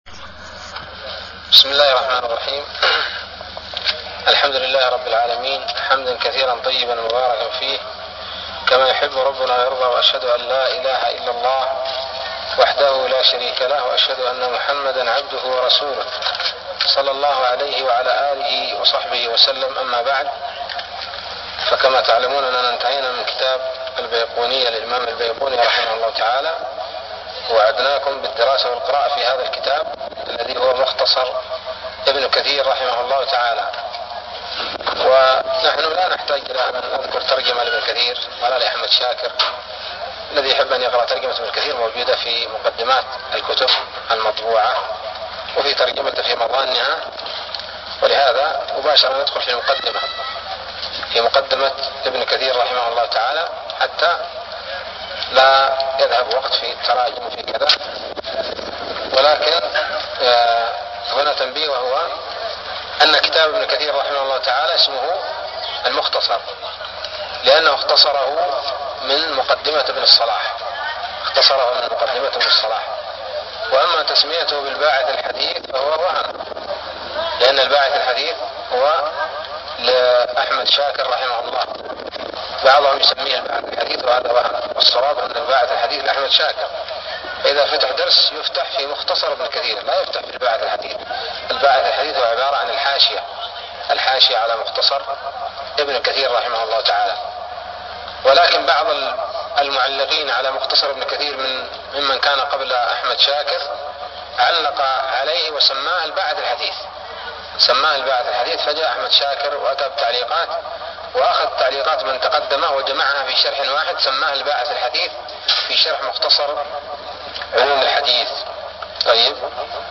الدرس الأول من الباعث الحثيث